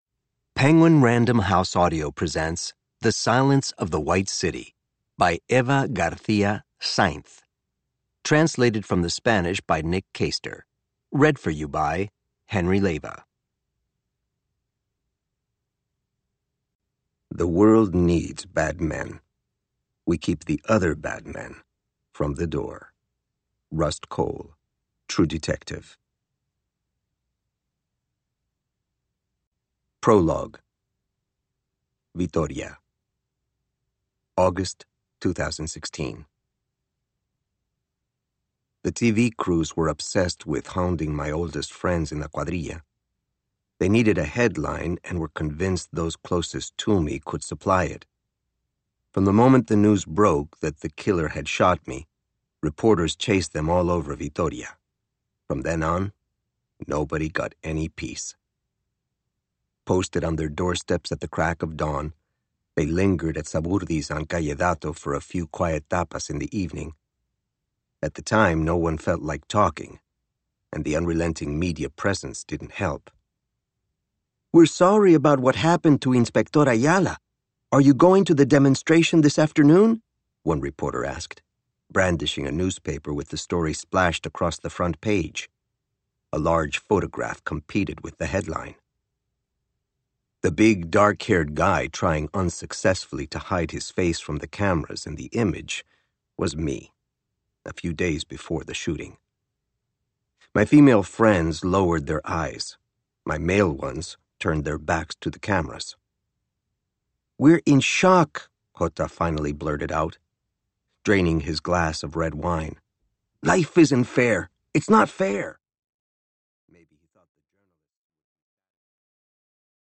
Unabridged
OverDrive MP3 Audiobook